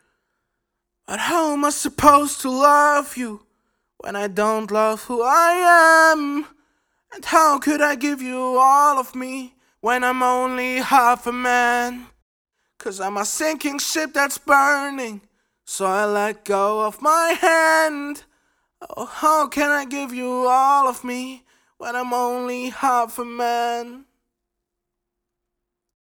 German Voice Over Artist
Sprechprobe: Industrie (Muttersprache):